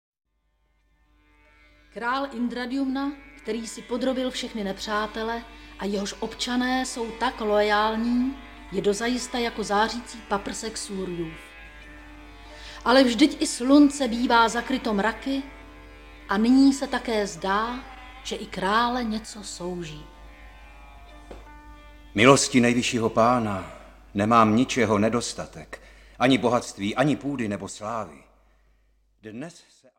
Ukázka z knihy
pribeh-o-dzaganatovi-audiokniha